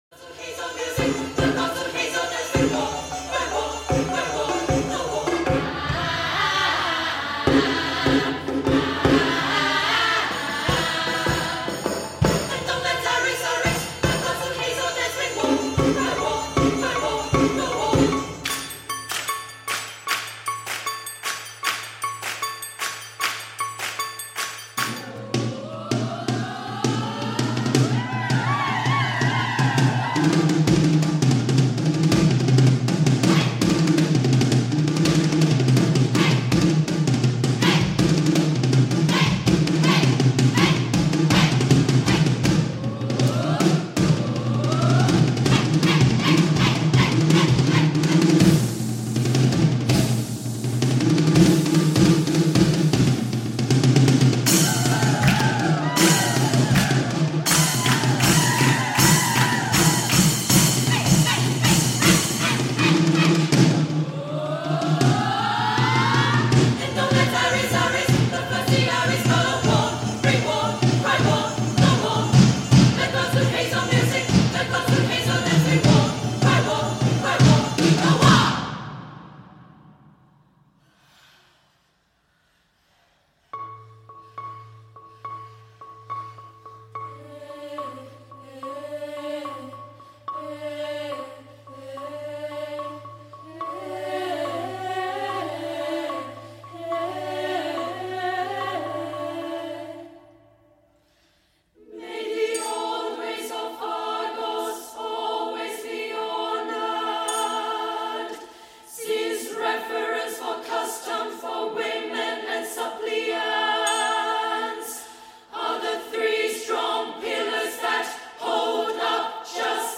These are sample recordings from the 2016 Production of the play done at the Lyceum Theater in Edinburgh, Scotland.
After pleading with the King of Argos for safe passage, he grants the Suppliants entry into the city. Their joy turns into a large, ecstatic dance, featured here.